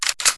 Paradise/sound/weapons/TargetOn.ogg